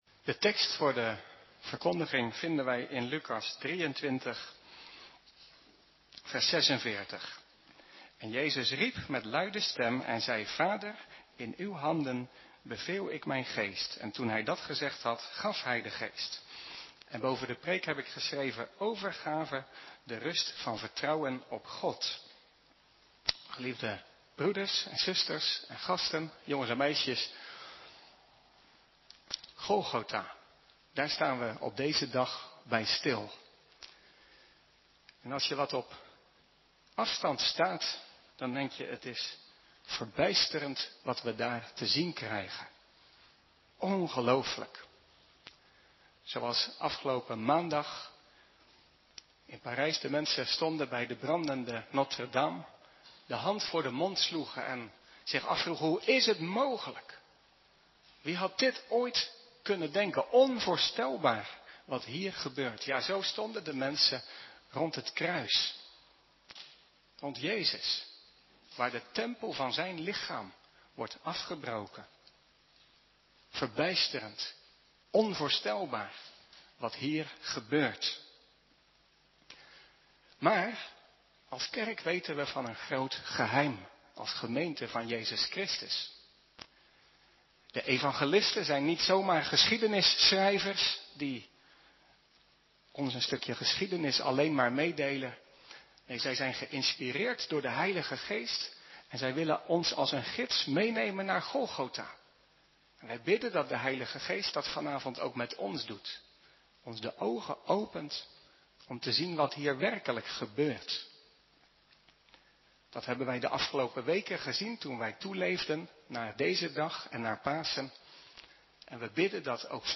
Preek over Lukas 23:46 – Kerk in IJsselmonde